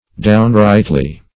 -- Down"right`ly, adv.